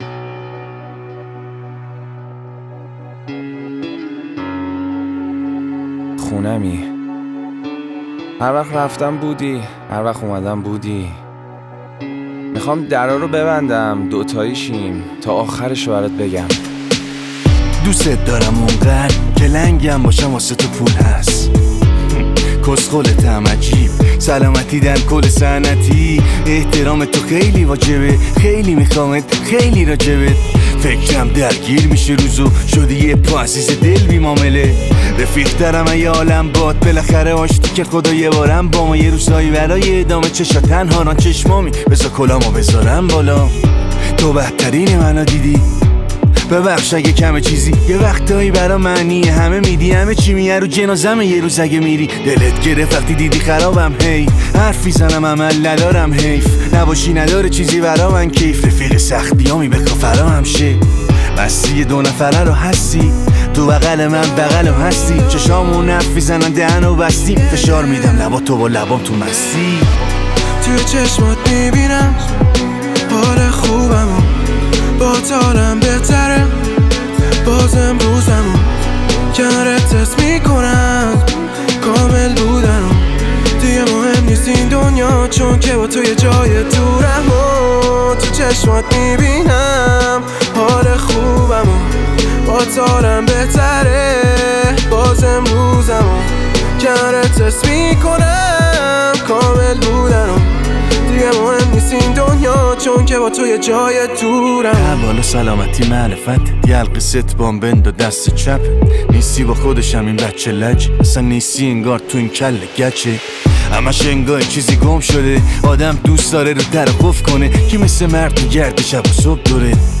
رپ
آهنگ با صدای زن